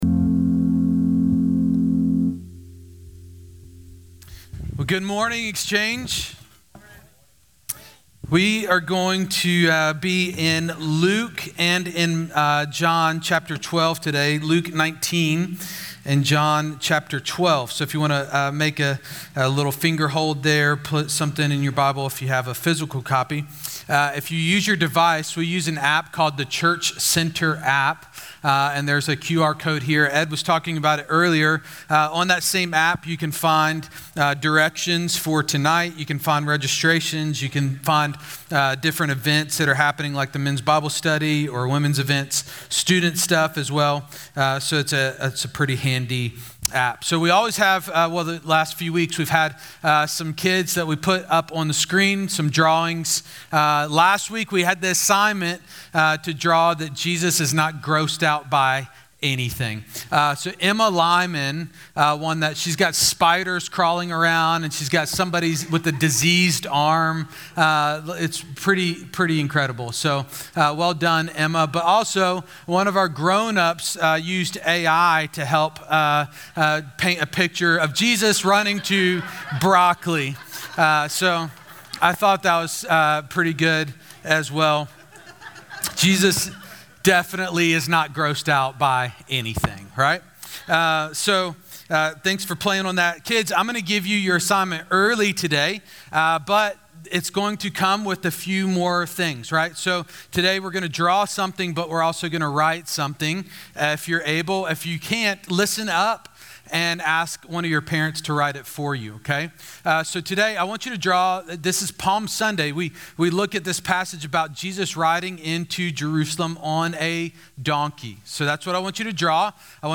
All Sermons Palm Sunday March 24